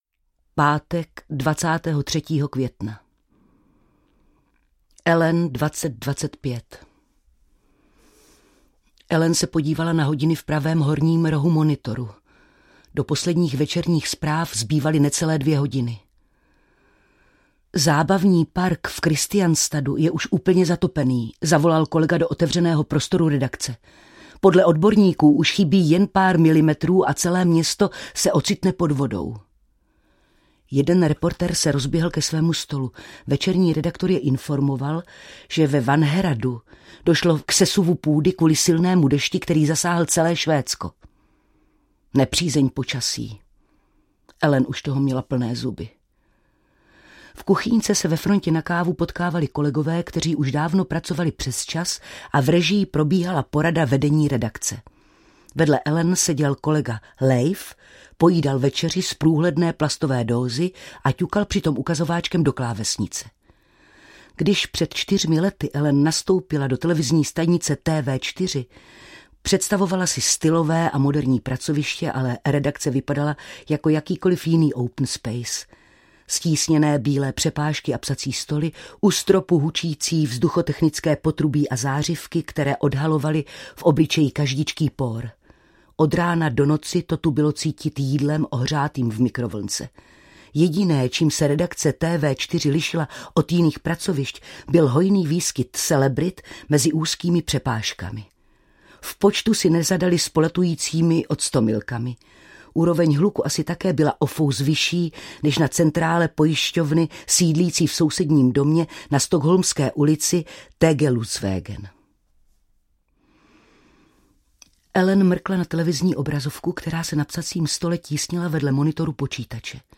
Déšť smývá stopy audiokniha
Ukázka z knihy
• InterpretSimona Peková